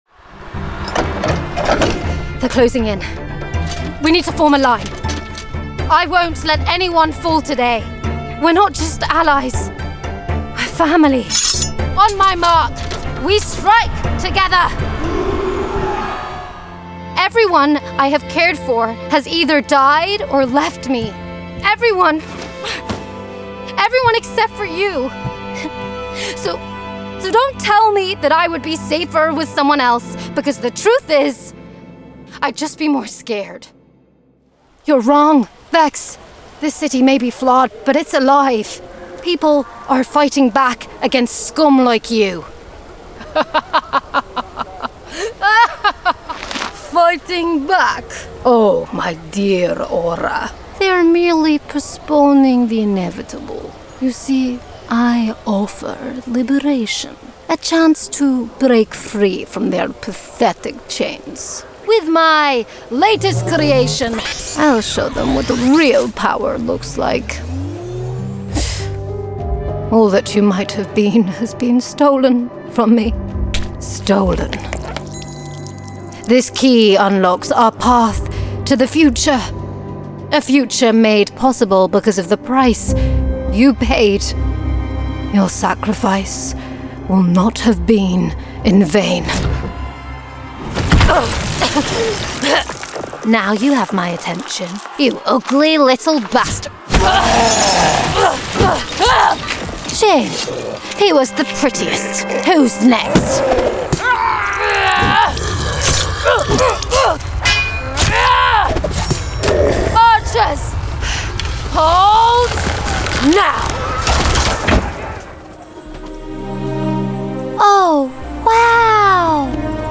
• Native Accent: RP